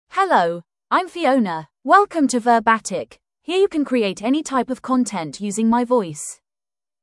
Fiona — Female English (United Kingdom) AI Voice | TTS, Voice Cloning & Video | Verbatik AI
Fiona is a female AI voice for English (United Kingdom).
Voice sample
Female
Fiona delivers clear pronunciation with authentic United Kingdom English intonation, making your content sound professionally produced.